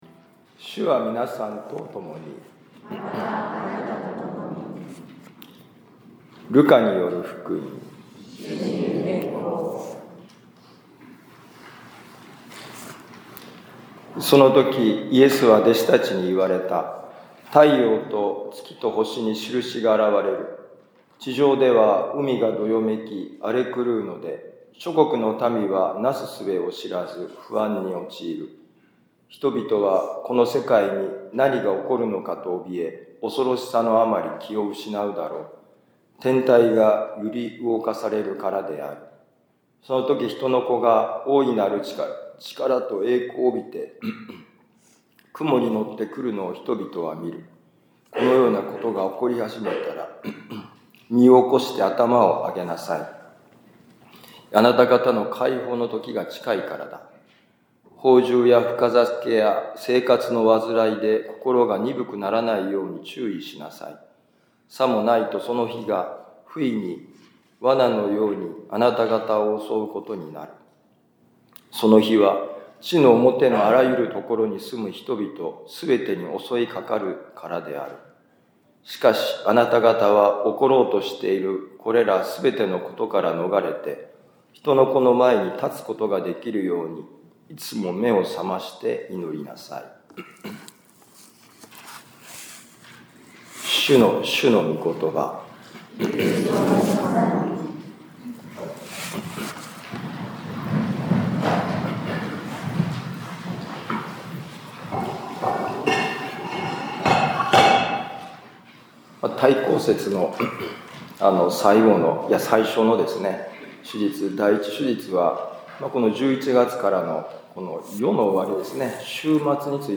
【ミサ説教】
ルカ福音書21章25-28、34-36節「希望の人」2024年12月1日待降節第１主日ミサ六甲カトリック教会